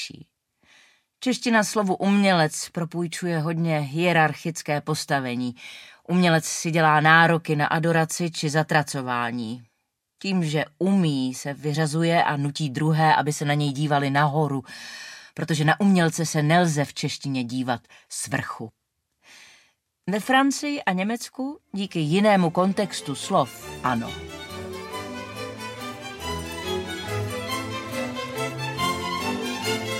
Audiokniha
Čte: Klára Cibulková